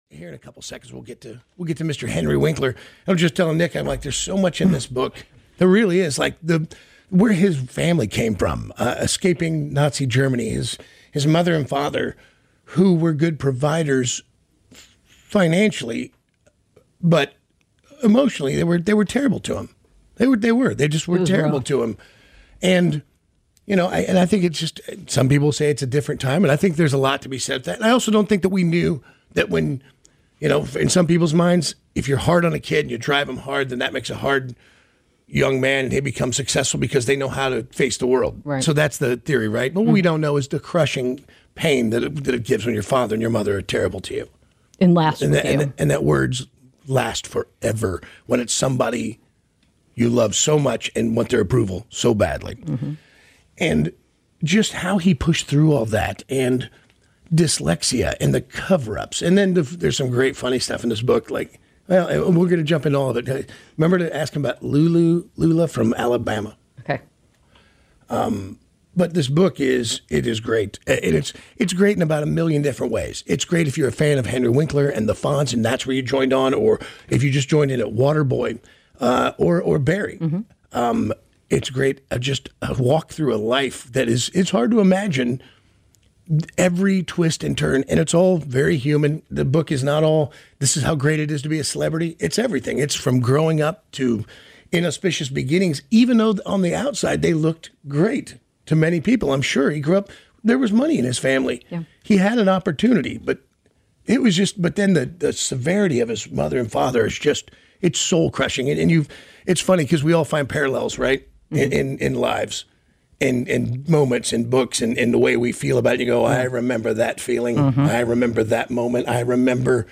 We spend an hour talking to the iconic and wonderful HENRY WINKLER!